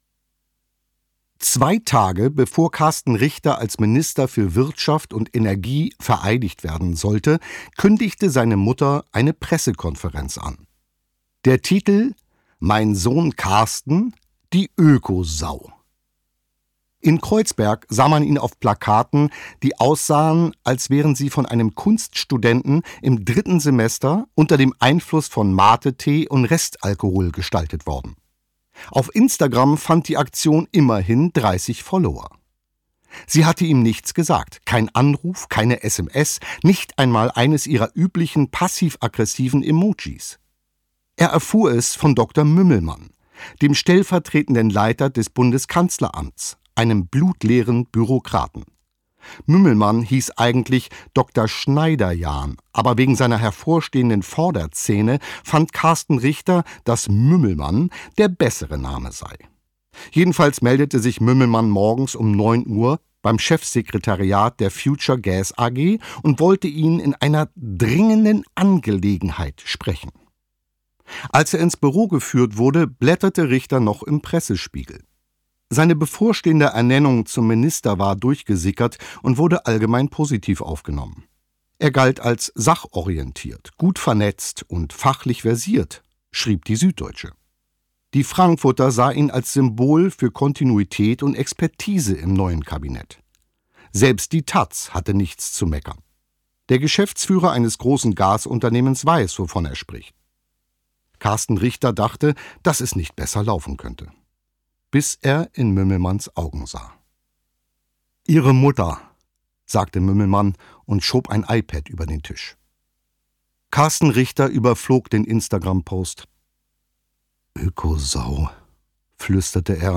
Gekürzt Autorisierte, d.h. von Autor:innen und / oder Verlagen freigegebene, bearbeitete Fassung.
Der unaufhaltsame Aufstieg des Ministers Karsten Richter Gelesen von: Bjarne Mädel
• Sprecher:innen: Bjarne Mädel